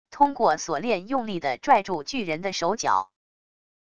通过锁链用力的拽住巨人的手脚wav音频